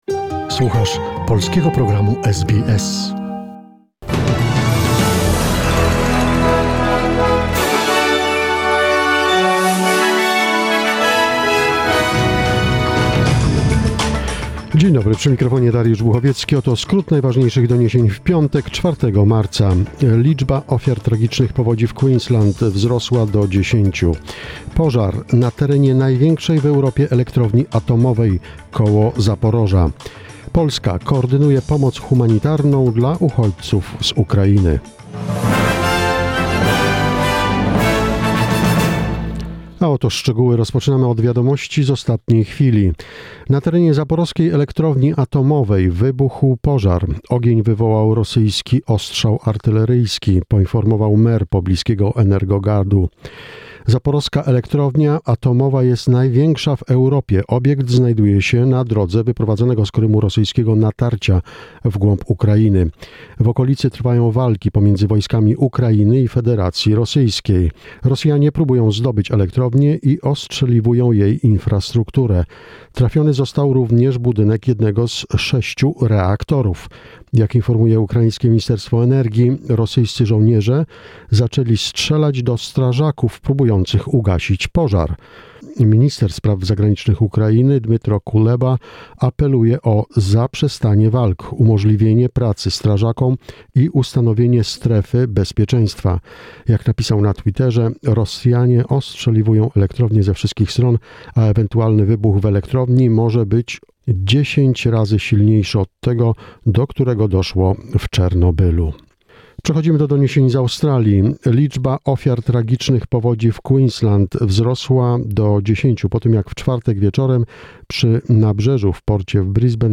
SBS News in Polish, 4 March 2021